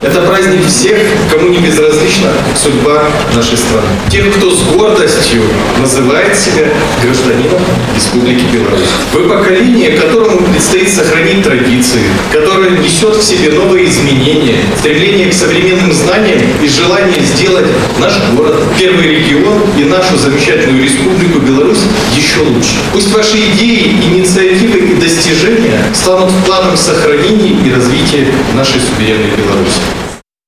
Затем в городском Доме культуры состоялось торжество и праздничный концерт, посвященный Дню Конституции.
Конституция гарантирует каждому гражданину свободы и права, необходимые для созидательного труда, достойной жизни и гармоничного всестороннего развития личности, — отметил председатель горисполкома Максим Антонюк.